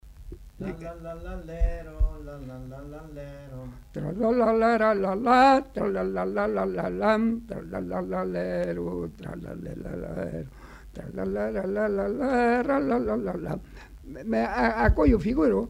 Aire culturelle : Savès
Genre : chant
Effectif : 1
Type de voix : voix d'homme
Production du son : fredonné
Danse : quadrille